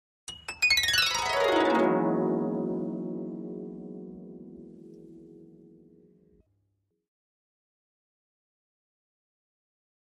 Harp, High Strings Short Descending Gliss, Type 3